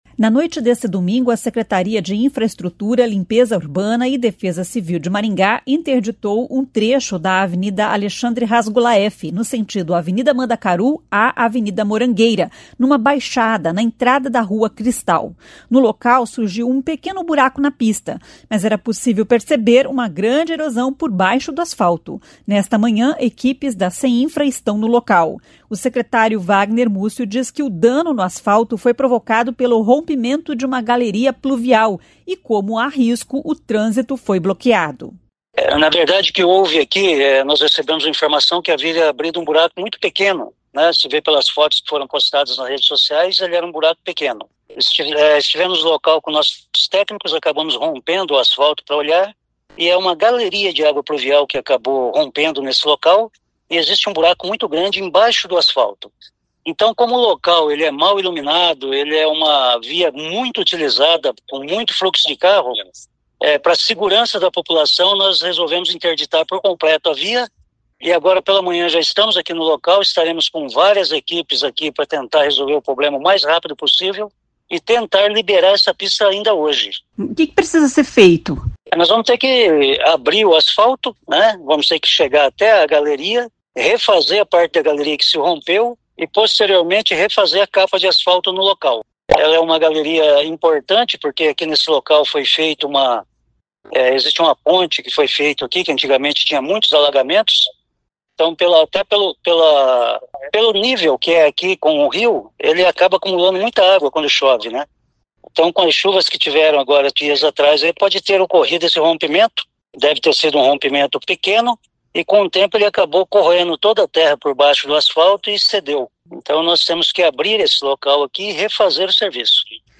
O secretário Vagner Mussio diz que o dano no asfalto foi provocado pelo rompimento de uma galeria pluvial e como há risco, o trânsito foi bloqueado.